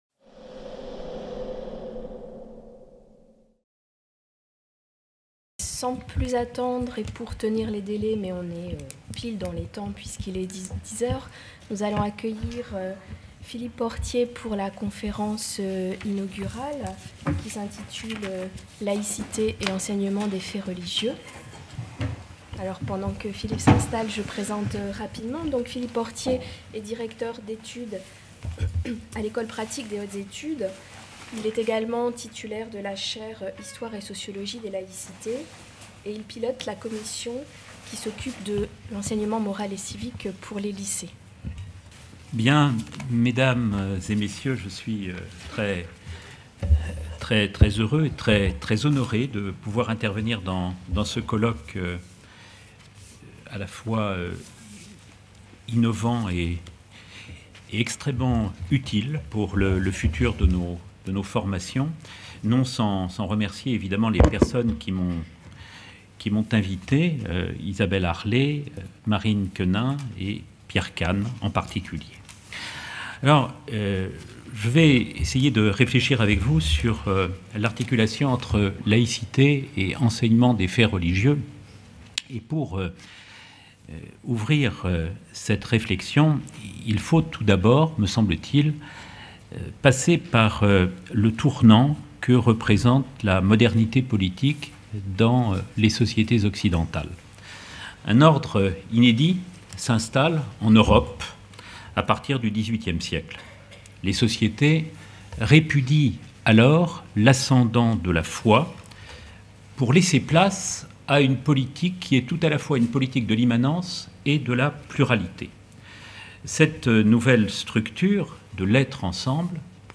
Conférence d’ouverture du colloque portant sur l’histoire de l’enseignement des faits religieux et de son articulation à l’éducation à la laïcité